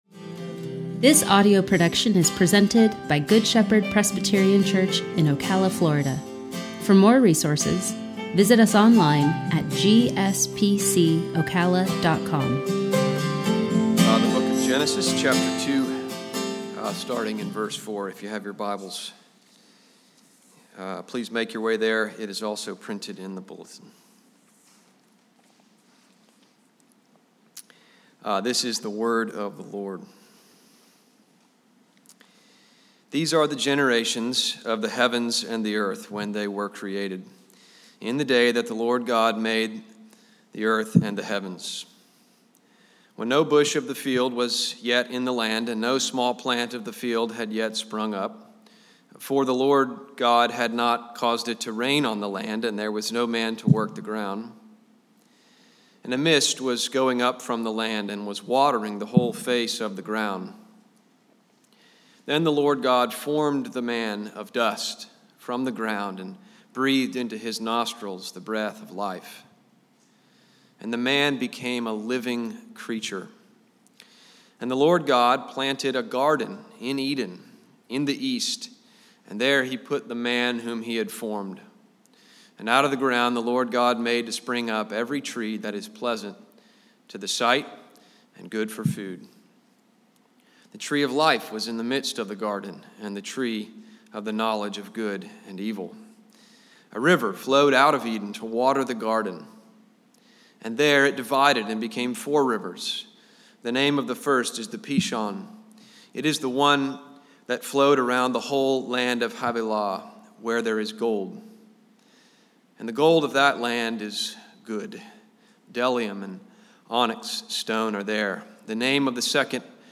sermon-5-23-21.mp3